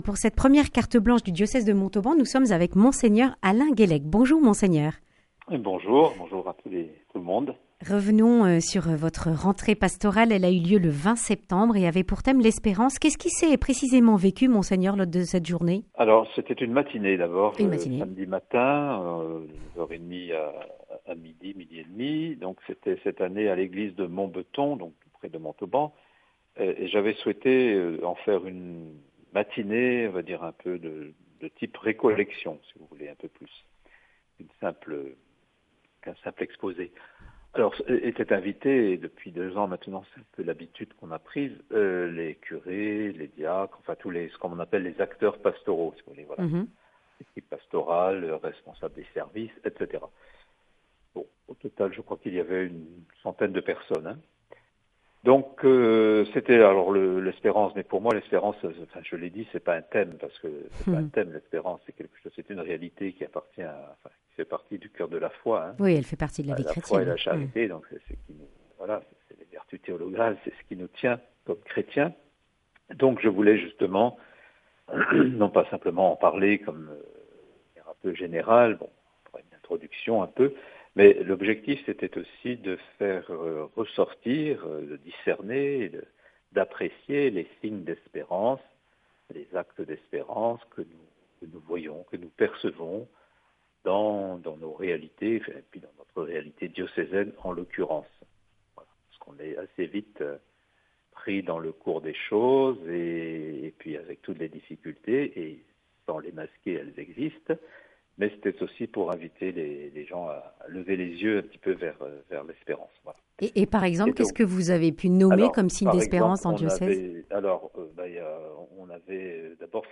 L’évêque de Montauban présente le bilan des récents rendez-vous du diocèse : rentrée pastorale, journée des néophytes, hommage à Monseigneur de Saint-Blanquat. Monseigneur Alain Guellec propose aussi de nombreux évènements à vivre en octobre et novembre.